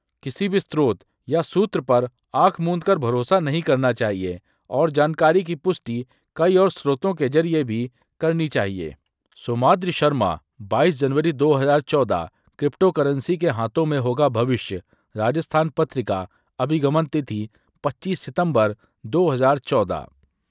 TTS_multilingual_audios